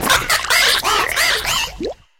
Cri de Palmaval dans Pokémon HOME.